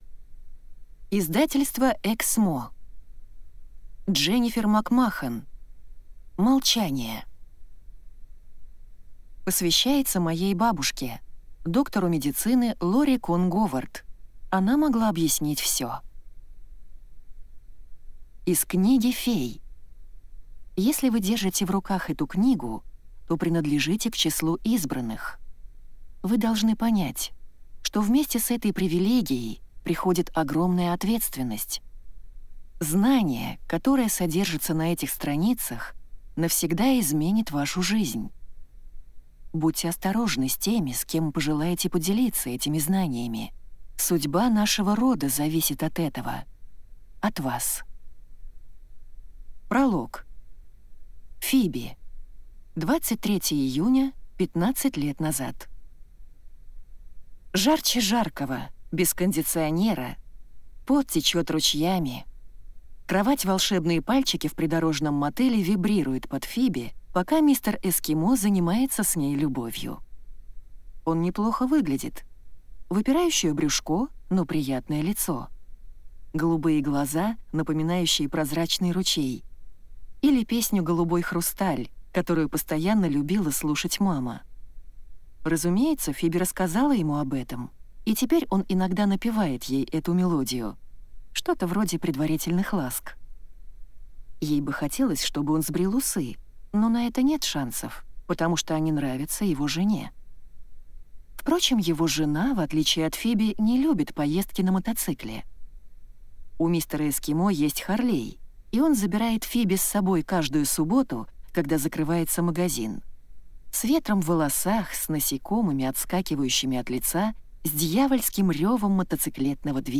Аудиокнига «Молчание» в интернет-магазине КнигоПоиск ✅ в аудиоформате ✅ Скачать Молчание в mp3 или слушать онлайн